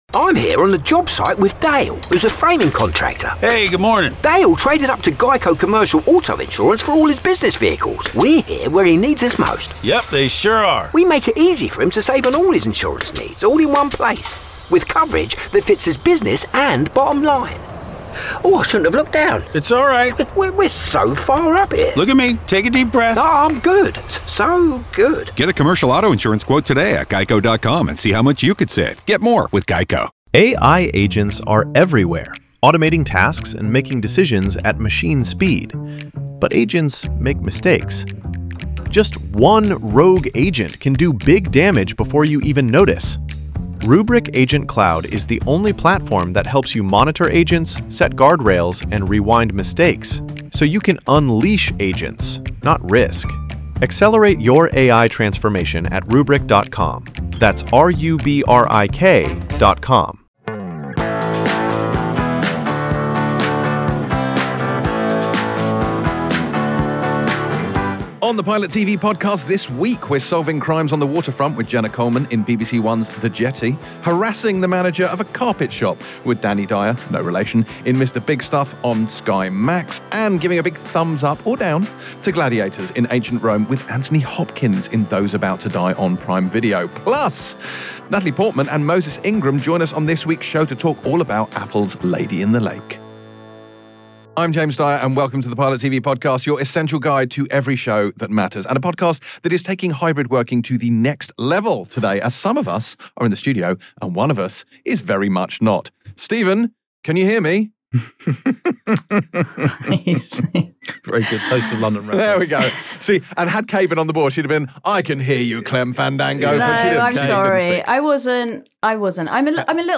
With guests Natalie Portman and Moses Ingram Pilot TV Empire Magazine Tv & Film, Tv Reviews 4.8 • 1.6K Ratings 🗓 15 July 2024 ⏱ 91 minutes 🔗 Recording | iTunes | RSS 🧾 Download transcript Summary Stars of Apple’s Lady In The Lake Natalie Portman and Moses Ingram join us on this week’s show despite a few technical hiccups. Plus we end up unearthing a cold case in BBC1’s very pointed Jenna Coleman police drama The Jetty, welcoming Danny Dyer back into the family with Mr. Bigstuff on Sky Max and getting our sandals on for some gladiatorial action in Those About To Die on Prime Video.